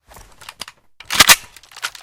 4aef571f59 Divergent / mods / MP153 Reanimation / gamedata / sounds / weapons / librarian_mp153 / unjam.ogg 18 KiB (Stored with Git LFS) Raw History
unjam.ogg